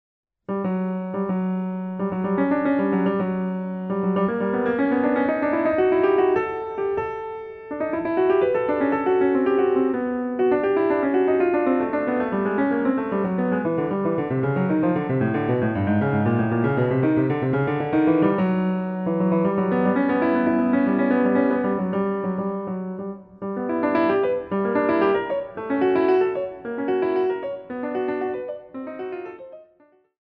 piano solo -